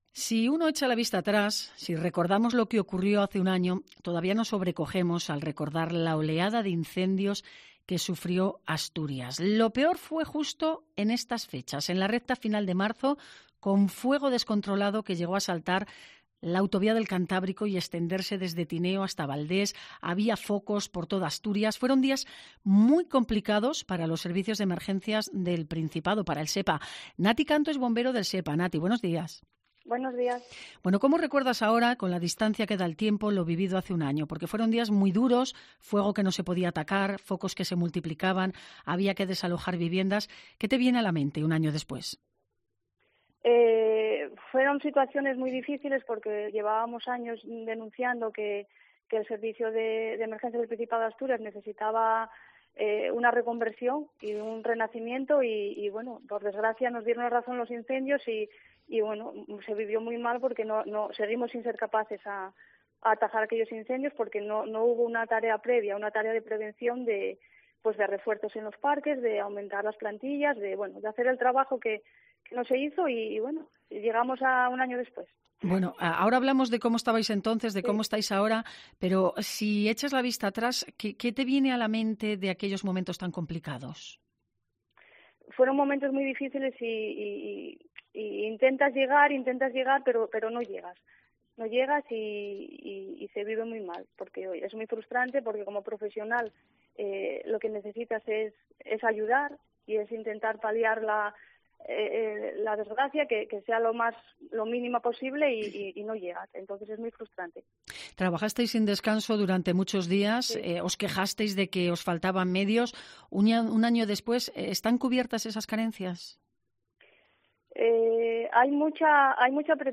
El testimonio de una bombera en COPE Asturias un año después de la oleada de incendios